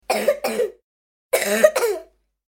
Маленькая девочка кашляет